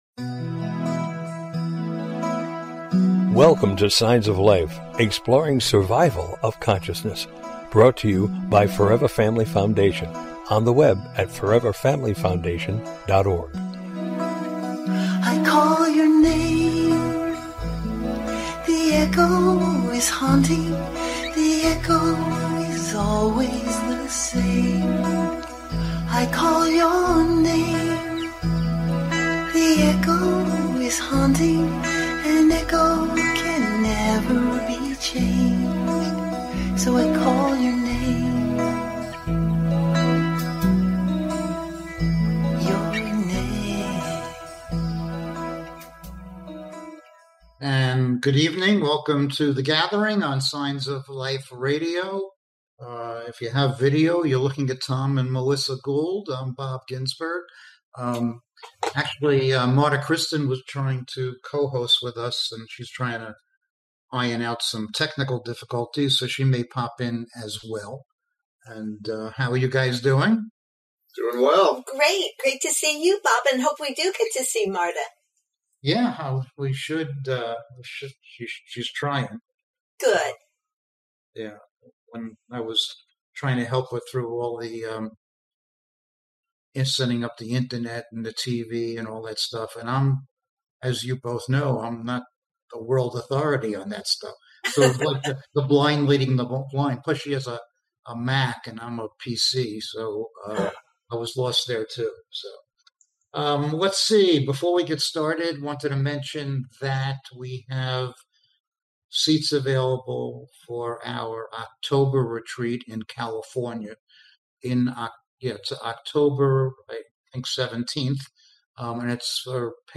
Join us this evening for an engaging live discussion on life after death and consciousness!